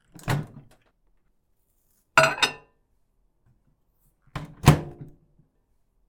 料理・台所の音
電子レンジ皿入れる